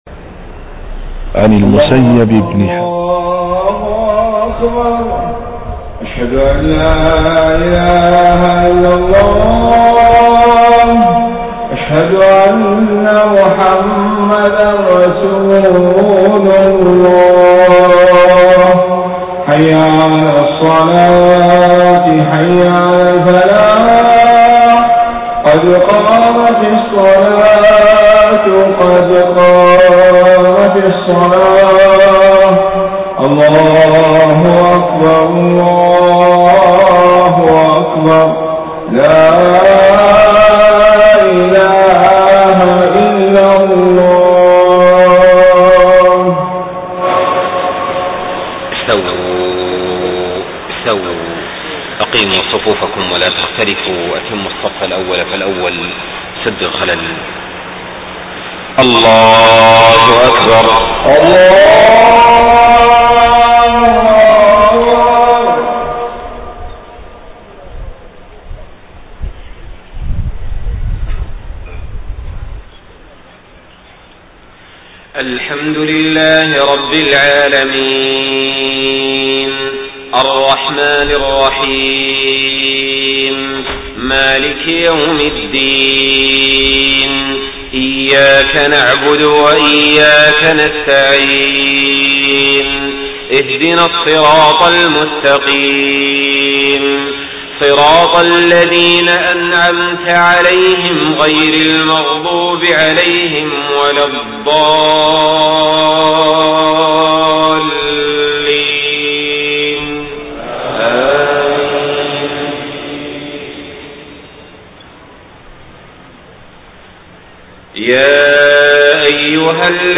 صلاة العشاء 12 ربيع الأول 1431هـ من سورة الحجرات 6-13 > 1431 🕋 > الفروض - تلاوات الحرمين